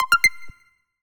Universal UI SFX / Basic Menu Navigation
Menu_Navigation01_Save.wav